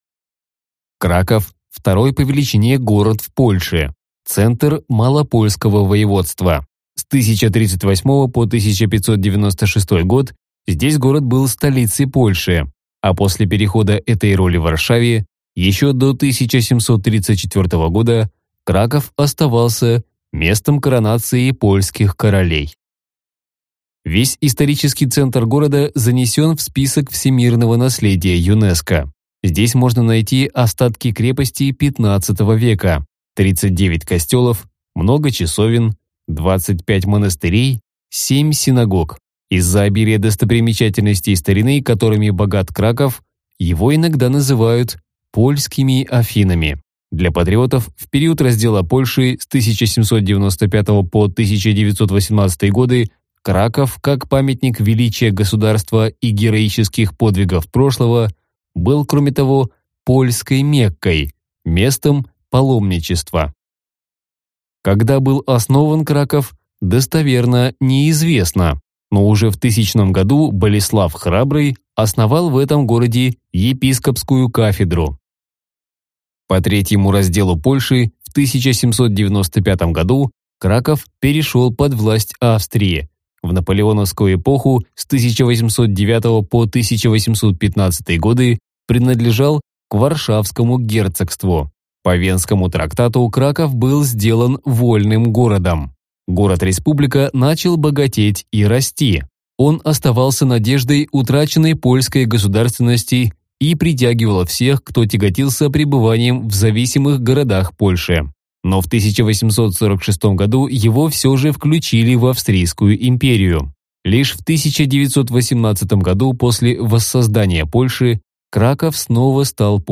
Аудиокнига Краков | Библиотека аудиокниг